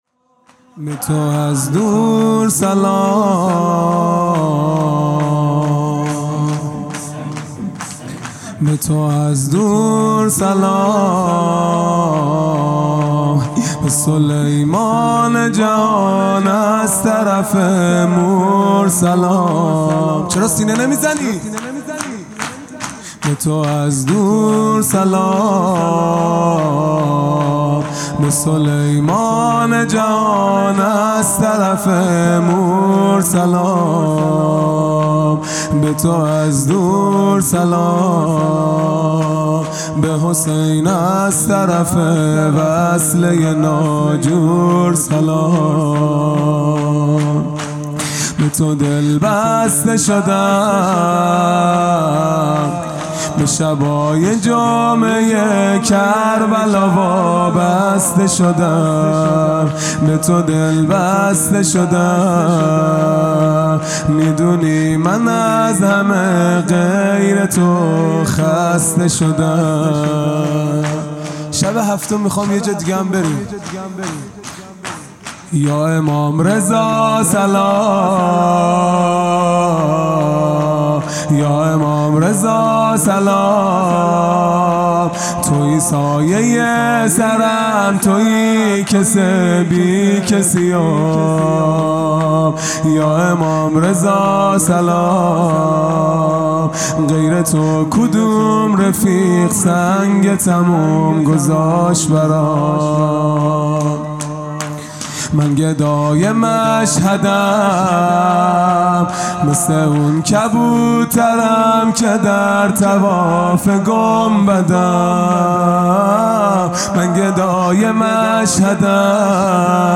خیمه گاه - هیئت بچه های فاطمه (س) - شور | به تو از دور سلام
محرم ۱۴۴۱ | شب هفتم